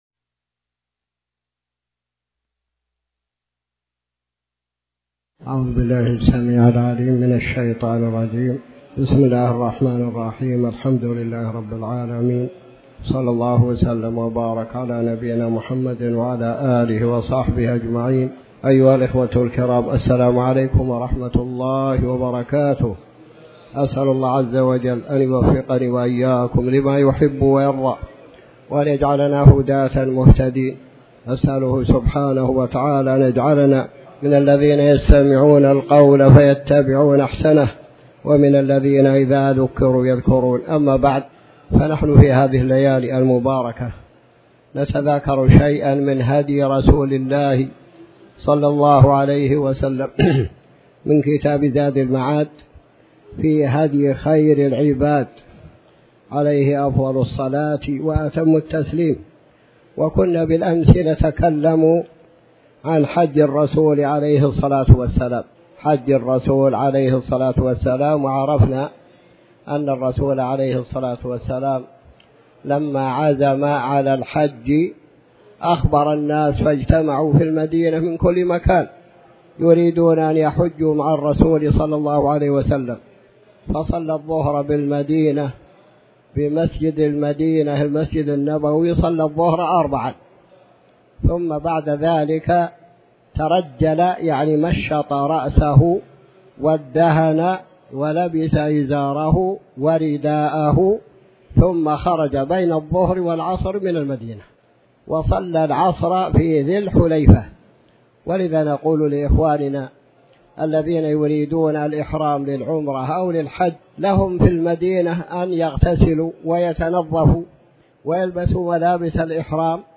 تاريخ النشر ٢٧ محرم ١٤٤٠ هـ المكان: المسجد الحرام الشيخ